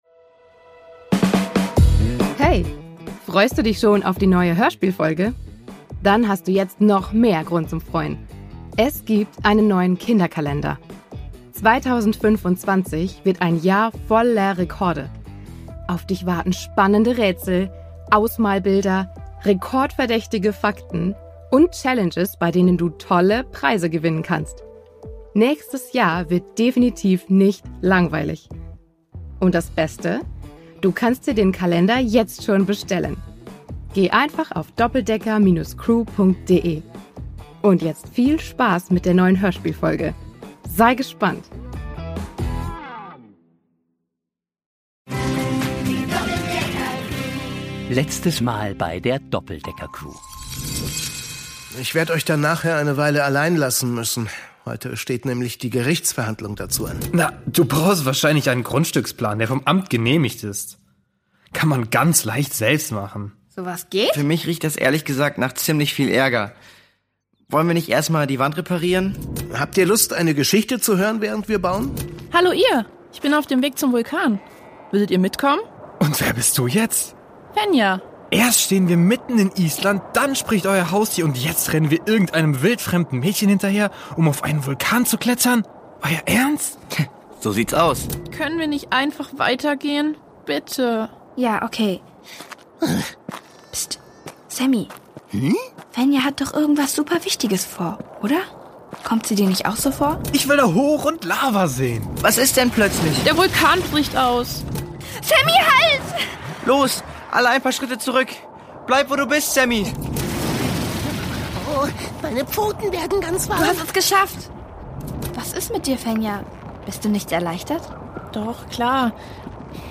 Island 1: Brodeln aus dem Inneren (2/3) | Die Doppeldecker Crew | Hörspiel für Kinder (Hörbuch) ~ Die Doppeldecker Crew | Hörspiel für Kinder (Hörbuch) Podcast